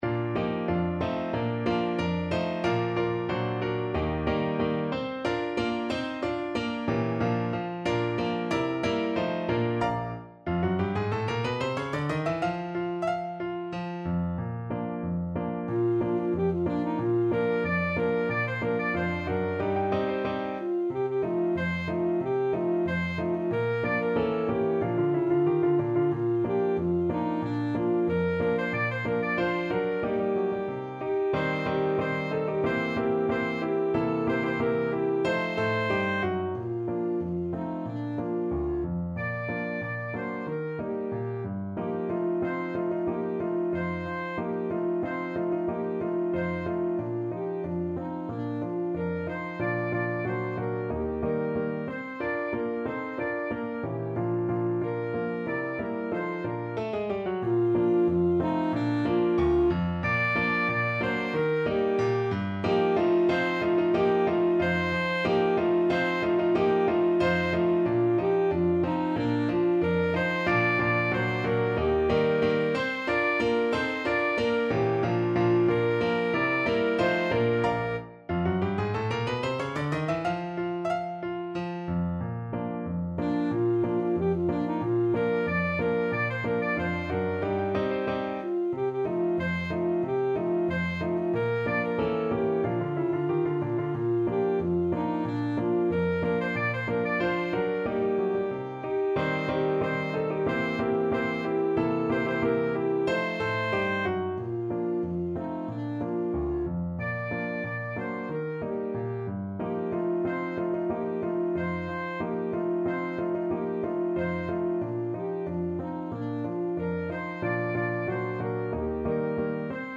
Alto Saxophone
2/4 (View more 2/4 Music)
Allegretto =92
D5-D6
Traditional (View more Traditional Saxophone Music)